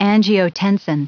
Prononciation du mot angiotensin en anglais (fichier audio)
Prononciation du mot : angiotensin